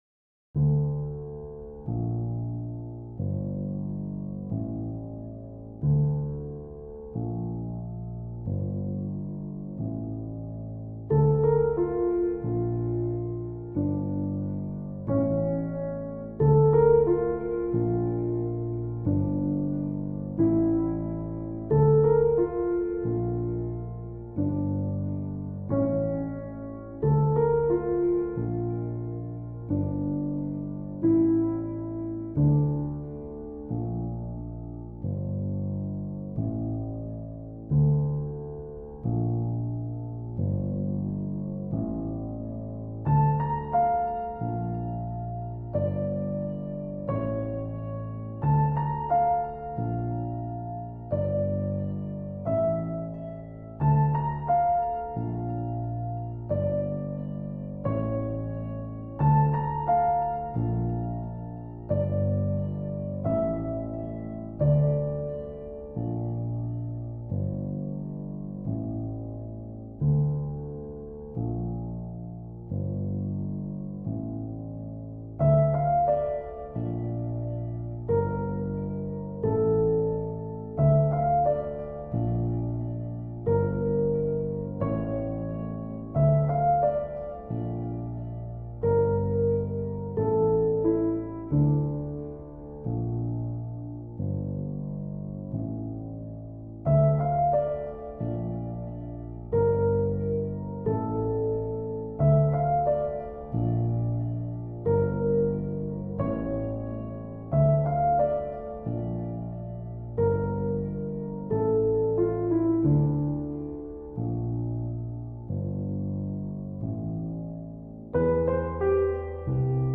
For piano and ambience.
An intimate and nostalgic music.
A tinge of Impressionism.